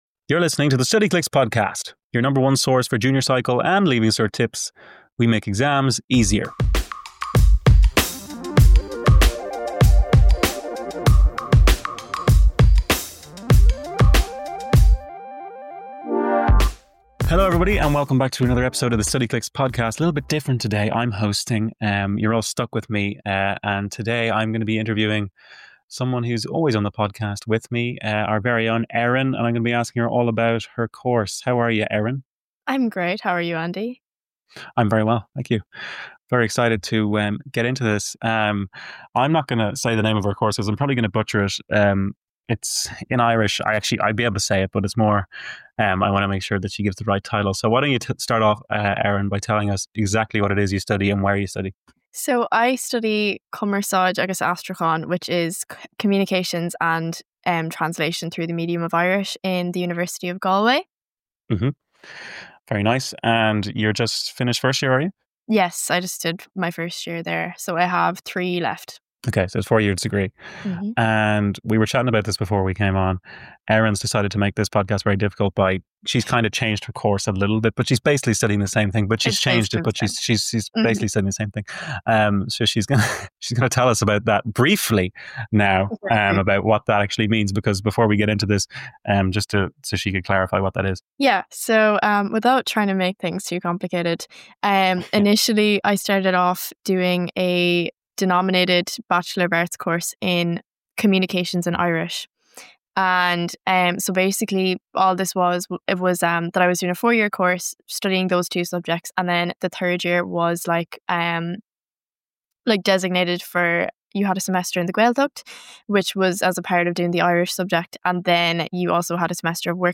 Cumarsáid Agus Aistriúchán in University of Galway (Interview with a college student)